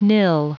Prononciation du mot nil en anglais (fichier audio)
Prononciation du mot : nil